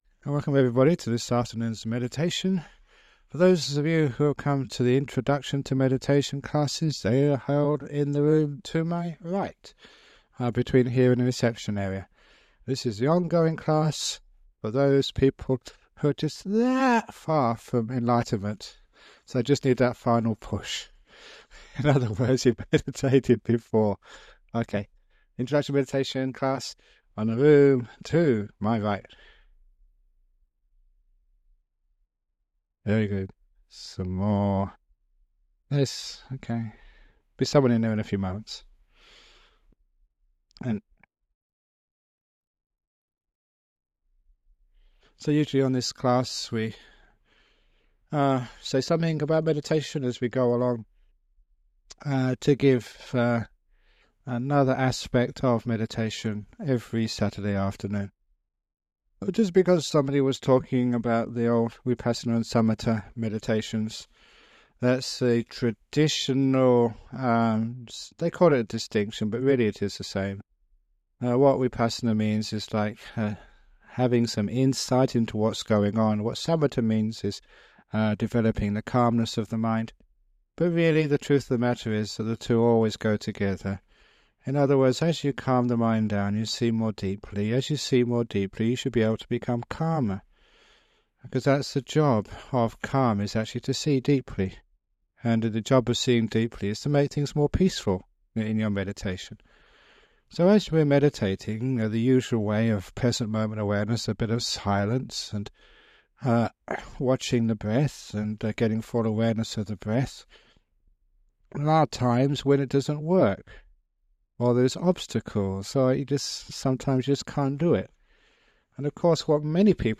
Guided Meditation with Ajahn Brahm
It includes a talk about some aspect of meditation followed by a 45 minute guided meditation. This guided meditation has been remastered and published by the Everyday Dhamma Network , and will be of interest to people who have started meditation but are seeking guidance to take it deeper.